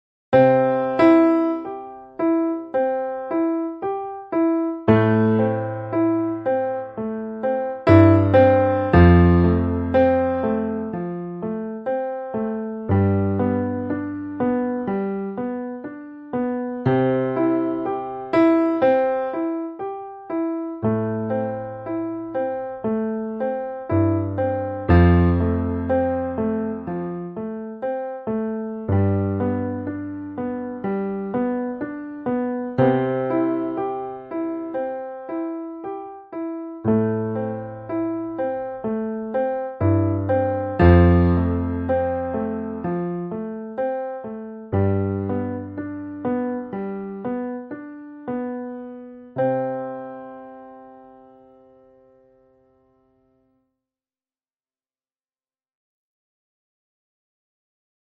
Rather than all 3 chord fingers together each note is played separately, so instead of for the C Chord C+E+G it’s C – E – G.
Piano 2 (BTW – Garageband records it loud, so lower your volume 🙂 )
(Apologies for those that like music in time)
piano2.mp3